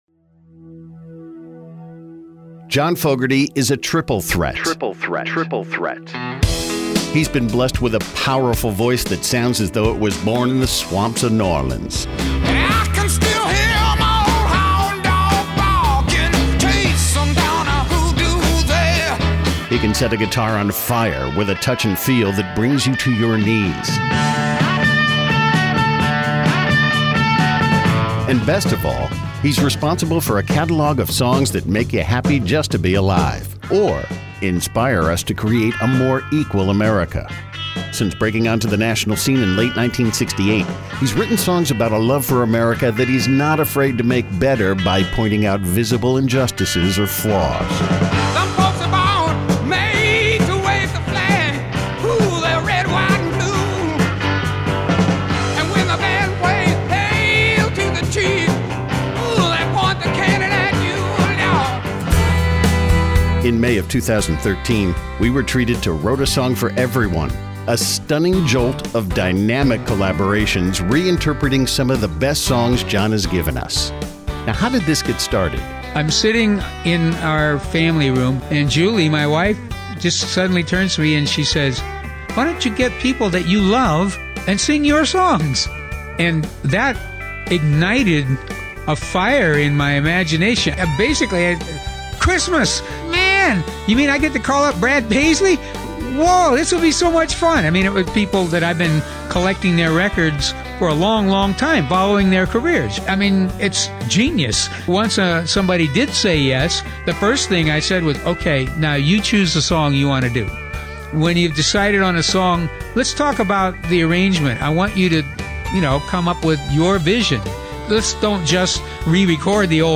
Have a listen to the engaging behind-the-scenes stories from John Fogerty about his all-star collaborations on the album 'Wrote a Song for Everyone'.